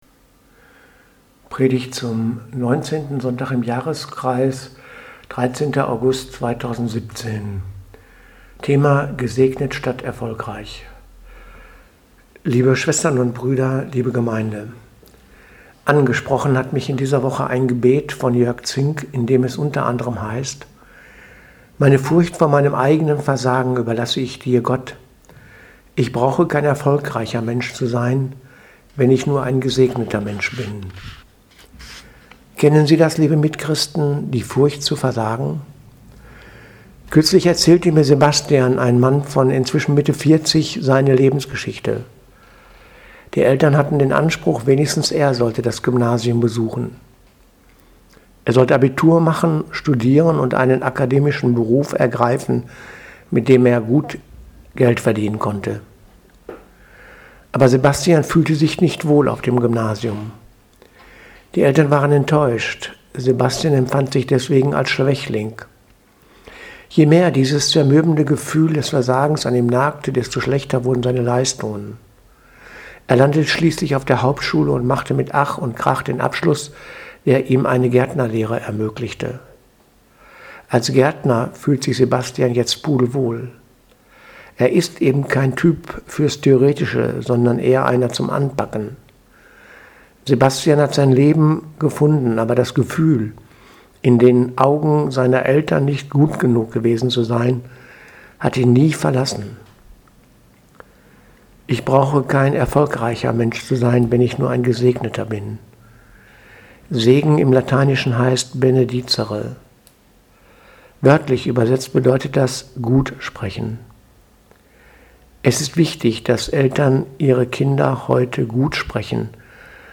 Predigt vom 13.8.2017 – Gesegnet statt erfolgreich
19. Sonntag im Jahreskreis